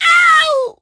tnt_guy_die_02.ogg